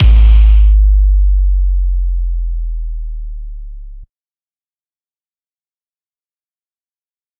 DMV3_808 7.wav